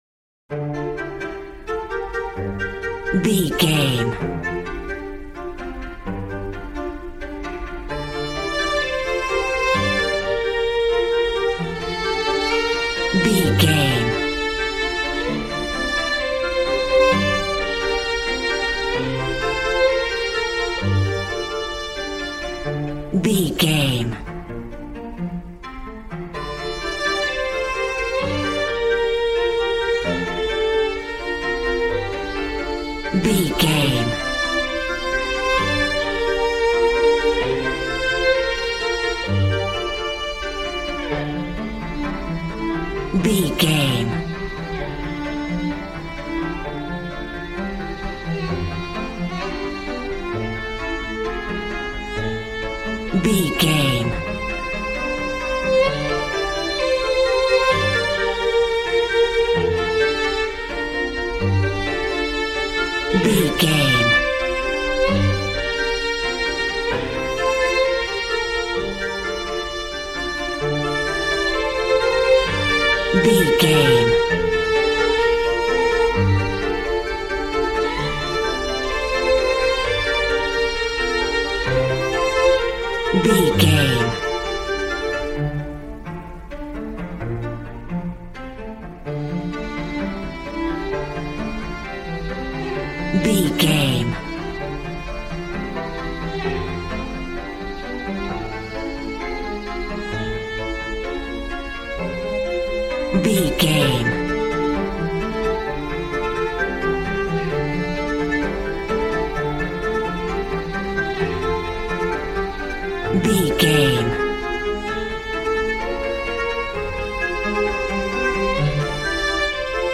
A warm and stunning piece of playful classical music.
Regal and romantic, a classy piece of classical music.
Aeolian/Minor
regal
piano
violin
strings